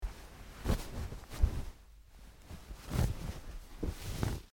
MovementOnBedSheet PE382101
Movement On Bed With Sheet Zuzz, X2